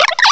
sovereignx/sound/direct_sound_samples/cries/oshawott.aif at 5954d662a5762d73b073731aa1d46feab2481c5c
oshawott.aif